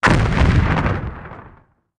otherdestroyed5.ogg